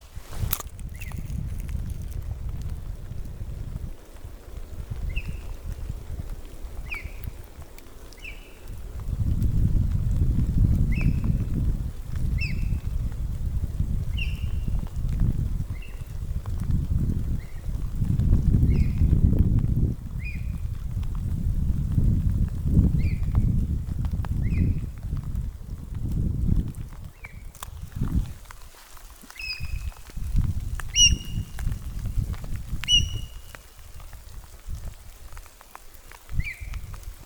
Putns (nenoteikts), Aves sp.
Administratīvā teritorijaStrenču novads
StatussDzirdēta balss, saucieni